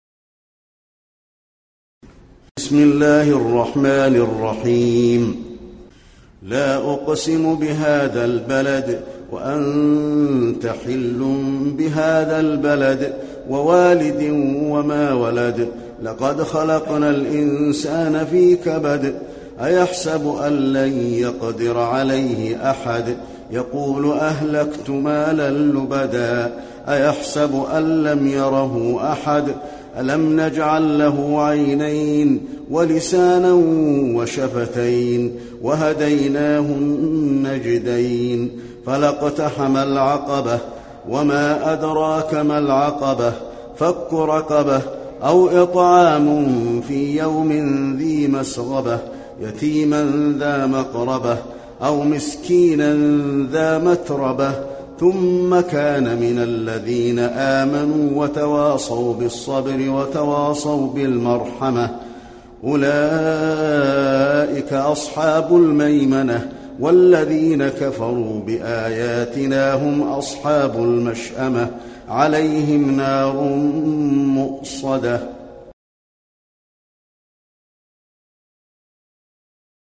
المكان: المسجد النبوي البلد